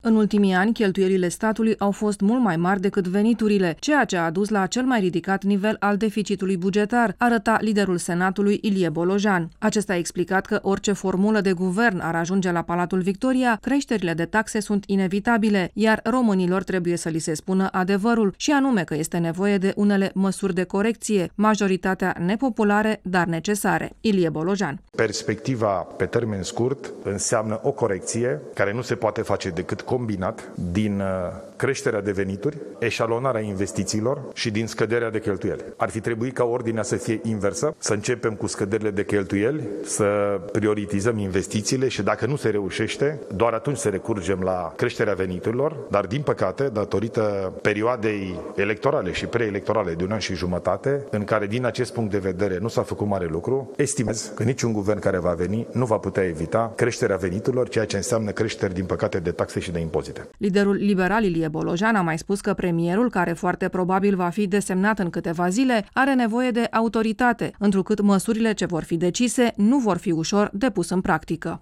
Este ceea ce a transmis ieri fostul preşedinte interimar Ilie Bolojan, într-o lungă conferinţă de presă în care a prezentat situaţia financiară dificilă în care a ajuns statul, după ani în care a cheltuit peste măsură.